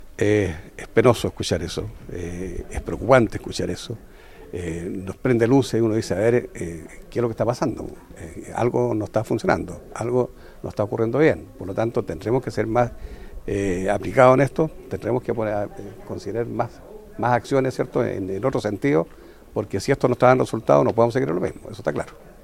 De esta manera y consultado por La Radio, el alcalde Jaime Bertín se mostró preocupado señalando que se prenden las luces para solicitar mayor control, e incluso consideró la situación como “penosa y preocupante“.